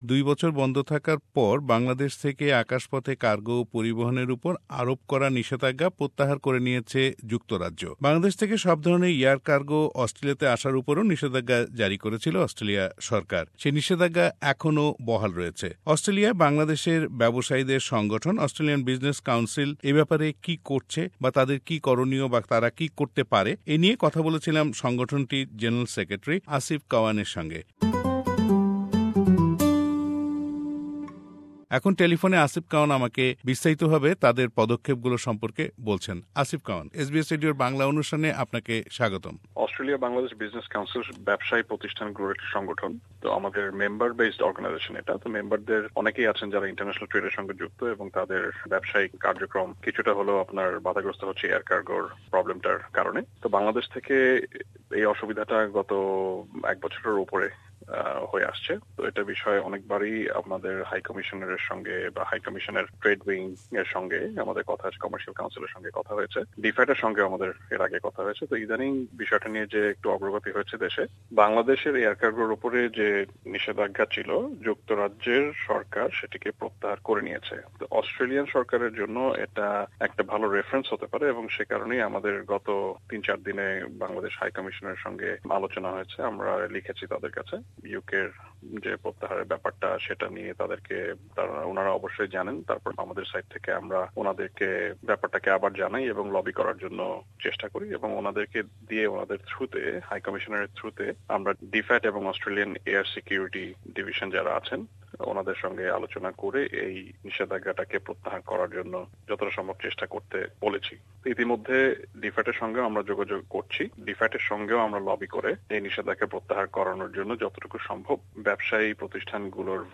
Uk lifts ban on direct Cargo flights from Bangladesh : Interview